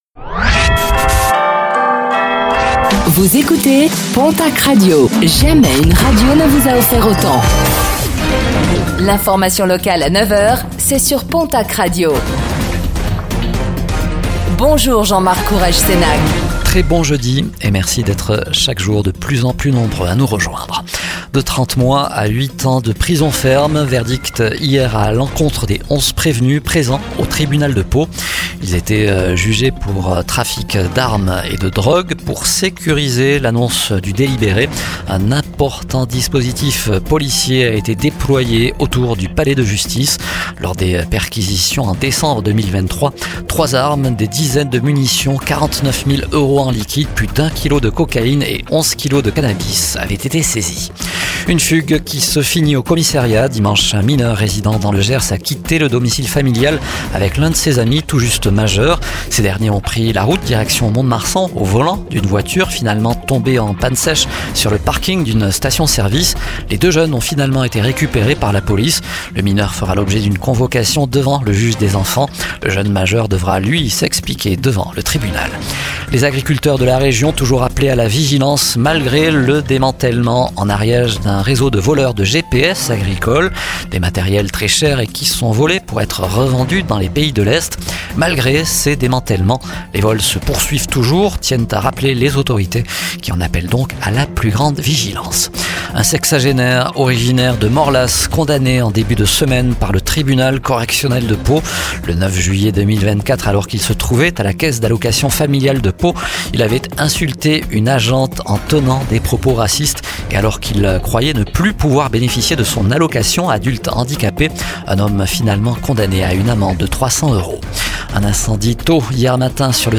Infos | Jeudi 23 octobre 2025